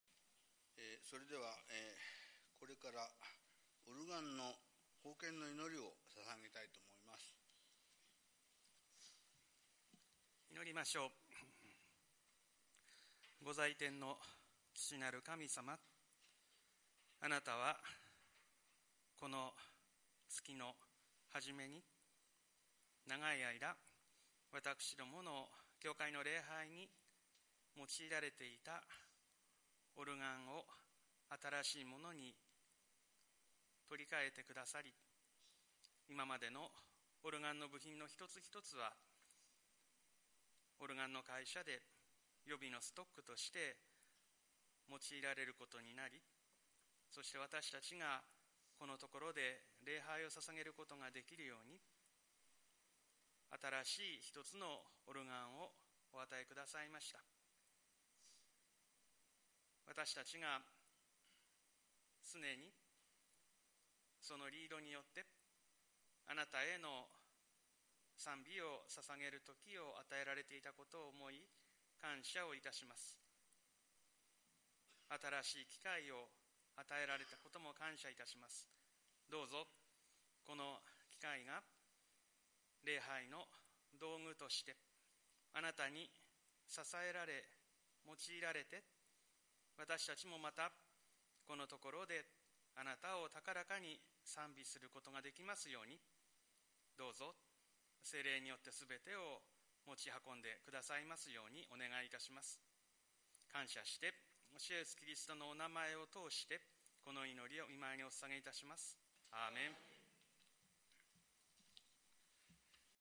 そして、7月21日礼拝後、 「オルガン奉献の祈り」が捧げられました。
1月19日金曜礼拝説教音声←祈り音声